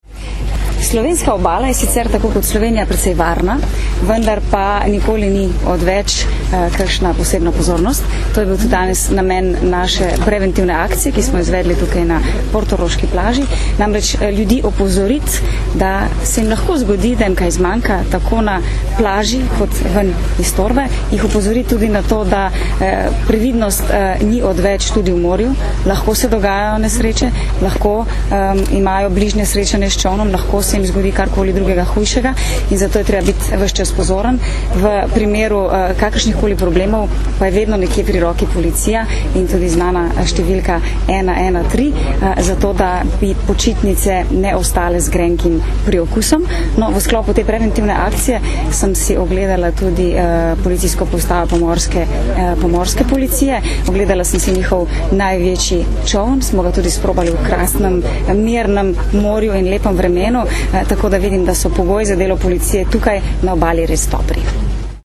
Na centralni plaži Portorož je bilo danes, 5. avgusta 2010, moč videti več policistk in policistov, ki so v okviru preventivne akcija za varno kopanje in potapljanje o tem govorili s tamkajšnjimi obiskovalci.
Zvočni posnetek izjave ministrice za notranje zadeve Katarine Kresal (mp3)